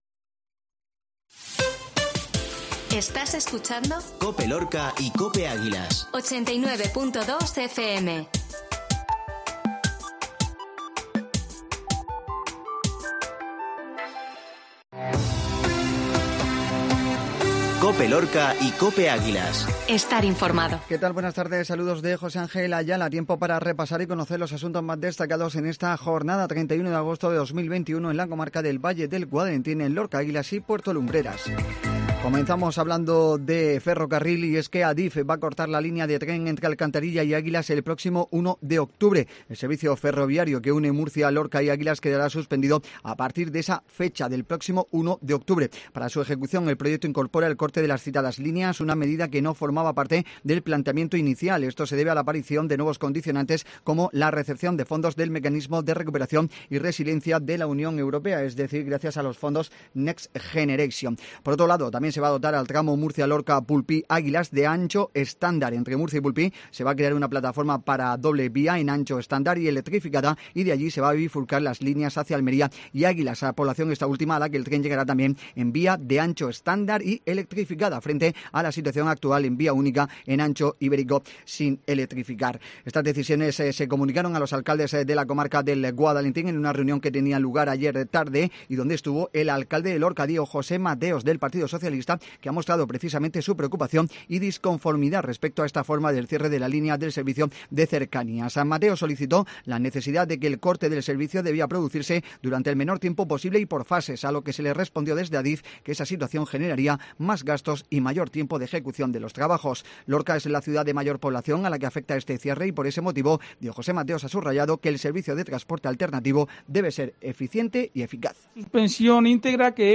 INFORMATIVO MEDIODIA COPE MARTES